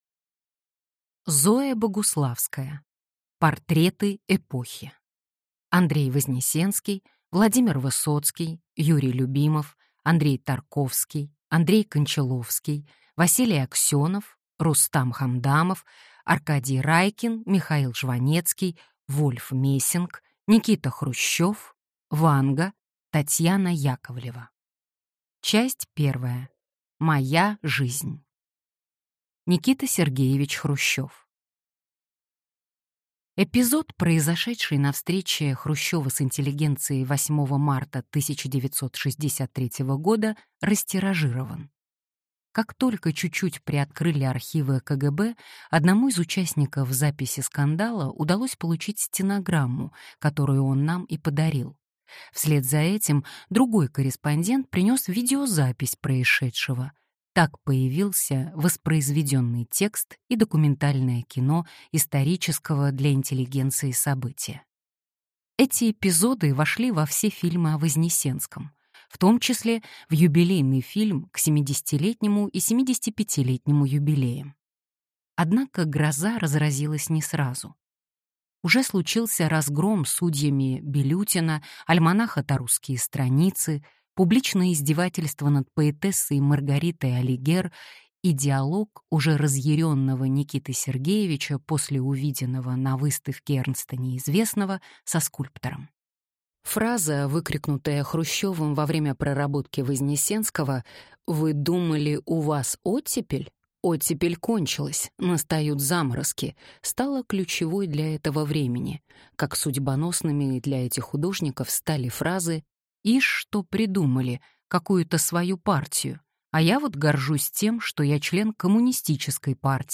Аудиокнига Портреты эпохи: Андрей Вознесенский, Владимир Высоцкий, Юрий Любимов…